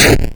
Player Lose Health.wav